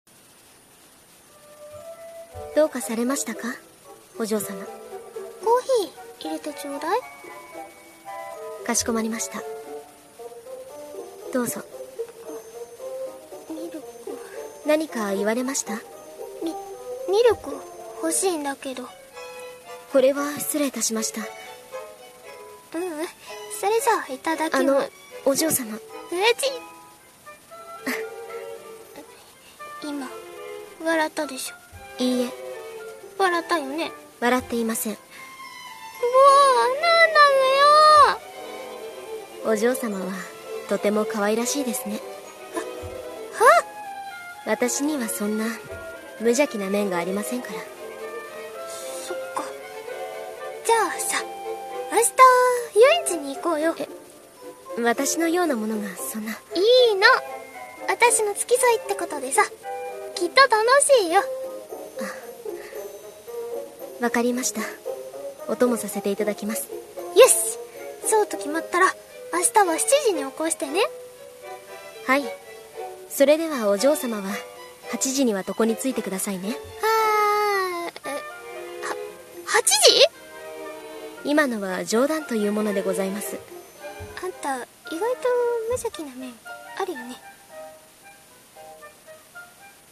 【コラボ声劇】クール？なメイドと優しいお嬢様